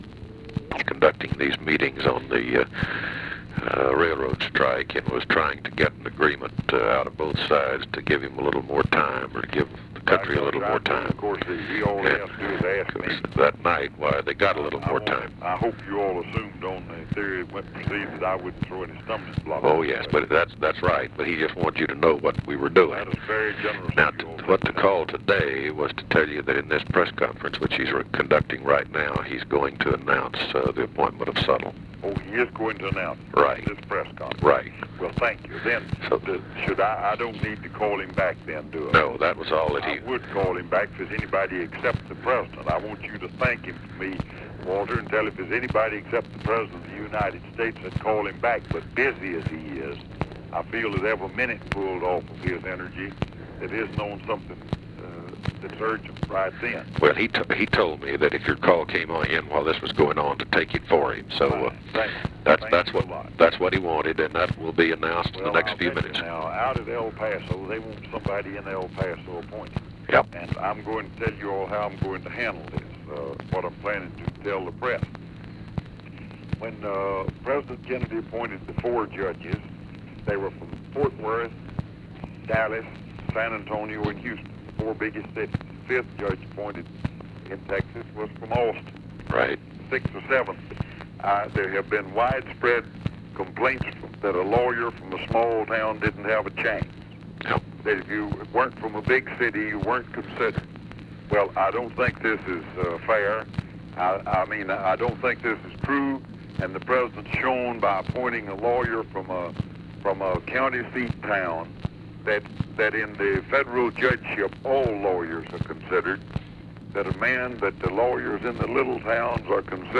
Conversation with RALPH YARBOROUGH
Secret White House Tapes